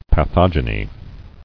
[pa·thog·e·ny]